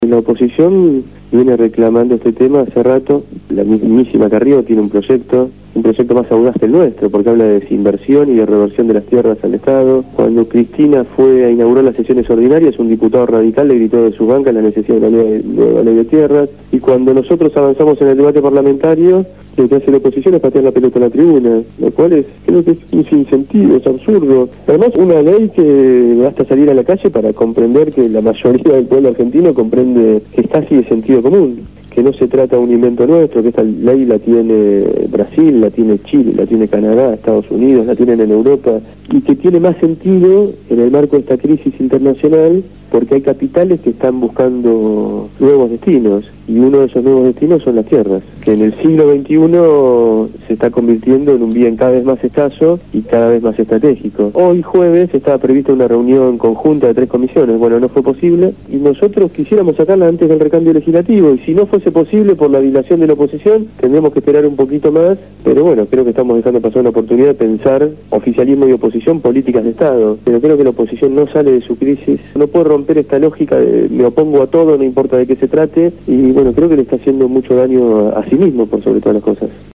Ariel Pasini se refirió en esta entrevista a la Asignación Universal por Hijo, las jubilaciones, el Banco del Sur, la Ley de Tierras y la economía argentina.